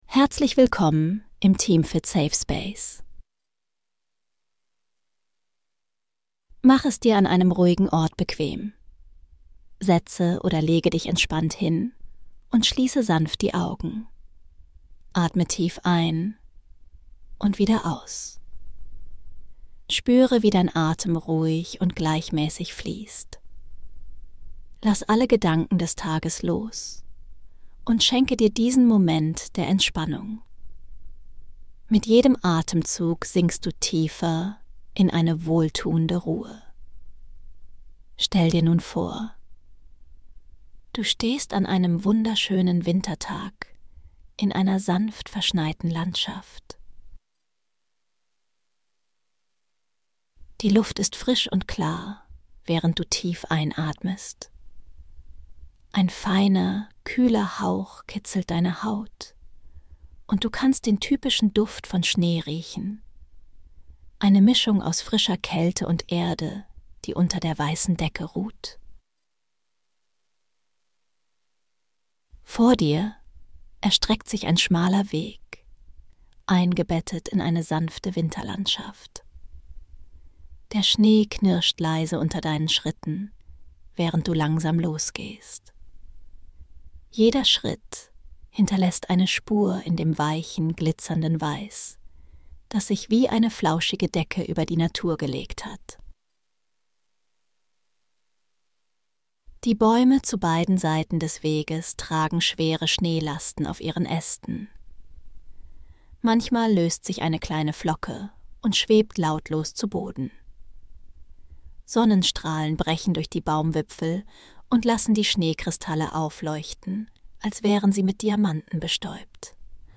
Eine Traumreise durch eine stille, verschneite Winterlandschaft